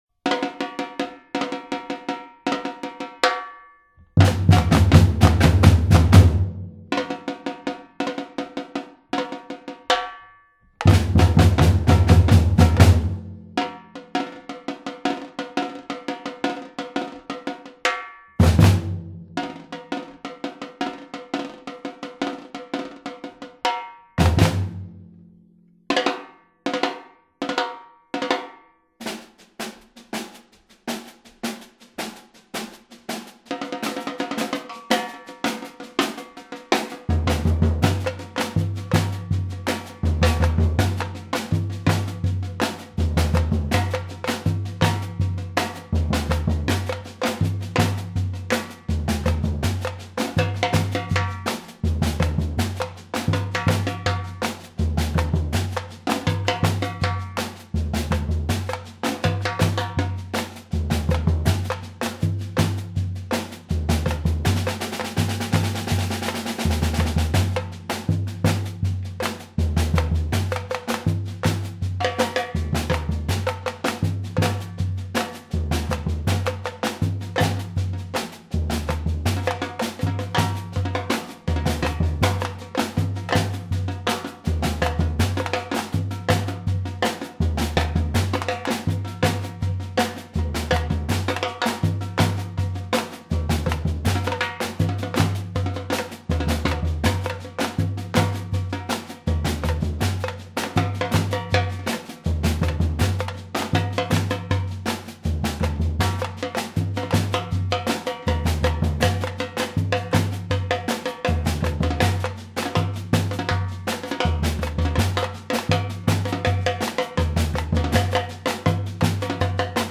BRAZILIAN PERCUSSION_